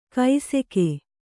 ♪ kai seke